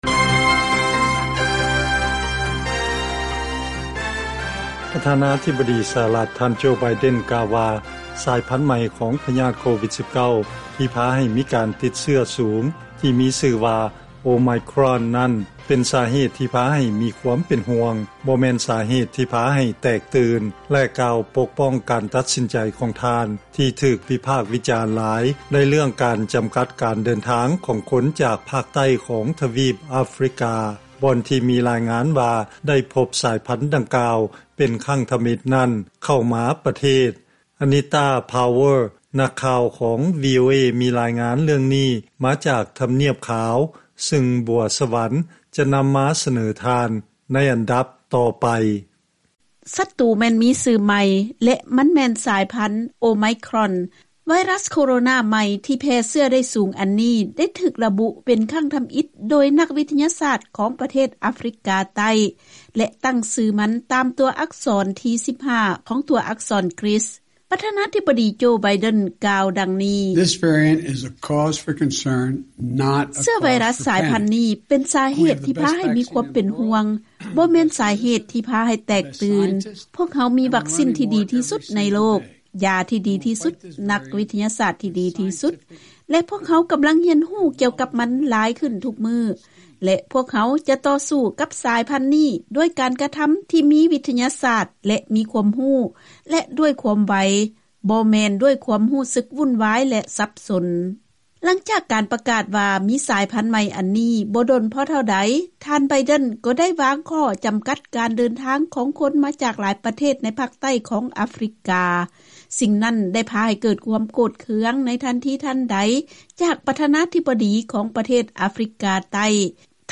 ເຊີນຟັງລາຍງານເລື້ອງປະທານາທິບໍດີໂຈ ໄບເດັນຮຽກຮ້ອງໃຫ້ຄົນໄປສັກວັກຊີນຂະນະທີ່ເລີ້ມມີໄວຣັສສາຍພັນໂອໄມຄຣອນຢູ່ນີ້